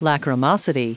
Prononciation du mot lachrymosity en anglais (fichier audio)
Prononciation du mot : lachrymosity